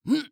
CK格挡02.wav
CK格挡02.wav 0:00.00 0:00.33 CK格挡02.wav WAV · 28 KB · 單聲道 (1ch) 下载文件 本站所有音效均采用 CC0 授权 ，可免费用于商业与个人项目，无需署名。
人声采集素材/男2刺客型/CK格挡02.wav